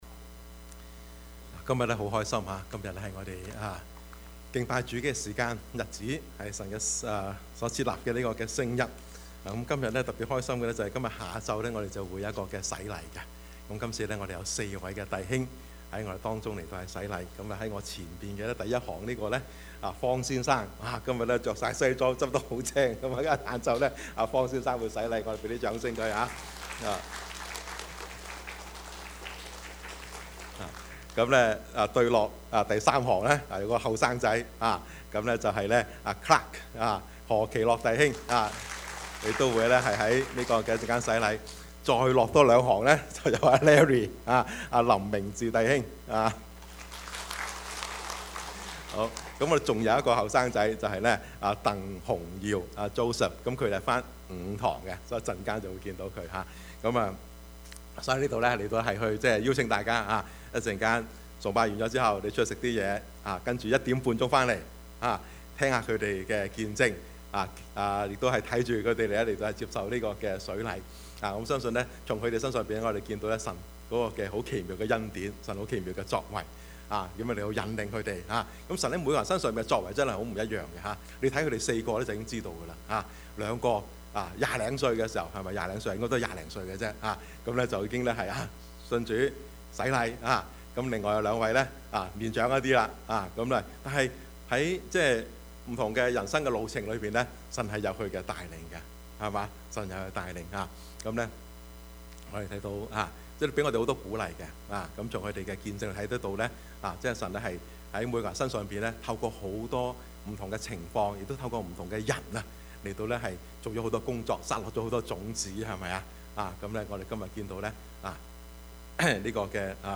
Service Type: 主日崇拜
Topics: 主日證道 « 患難與榮耀 婚姻與兒女 »